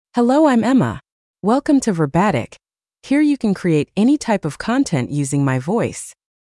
Emma — Female English (United States) AI Voice | TTS, Voice Cloning & Video | Verbatik AI
Emma is a female AI voice for English (United States).
Voice: EmmaGender: FemaleLanguage: English (United States)ID: emma-en-us
Voice sample
Listen to Emma's female English voice.
Emma delivers clear pronunciation with authentic United States English intonation, making your content sound professionally produced.